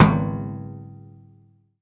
metal_drum_impact_thud_02.wav